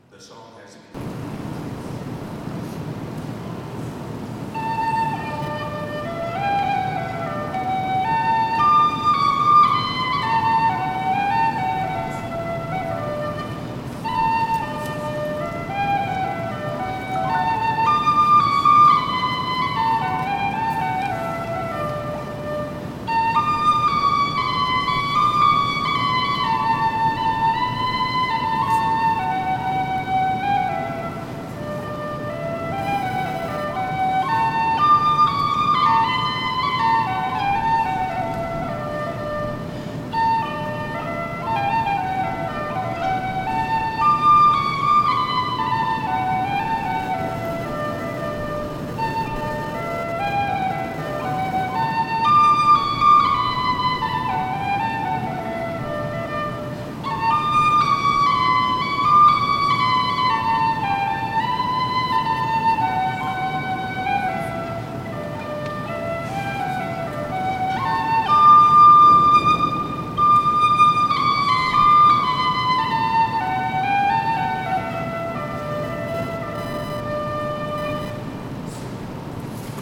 Diocesan Choirs "For God And Country" Concert - September 11, 2022
Piano